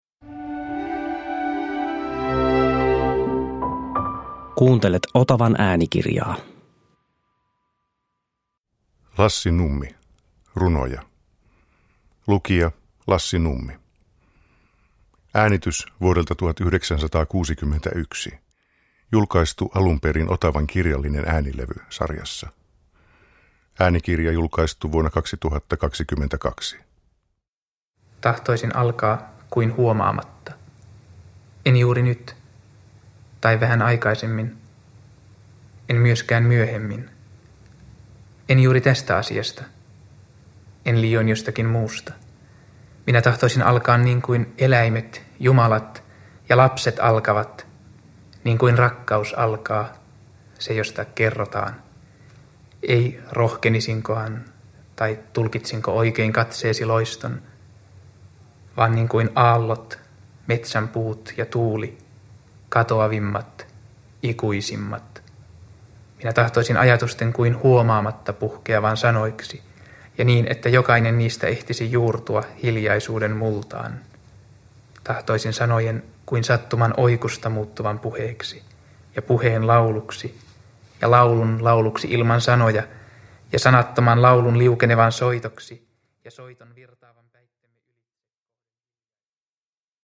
Runoja – Ljudbok – Laddas ner
Tällä äänitteellä vuodelta 1961 Nummi lukee runojaan kokoelmista Intohimo olemassaoloon, Tahdon sinun kuulevan ja Taivaan ja maan merkit. Äänitteen avaa Nummen valikoima runoelmasta Chaconne, joka julkaistiin jo 1956 mutta kuulostaa yhä modernilta.
Uppläsare: A. W. Yrjänä, Lassi Nummi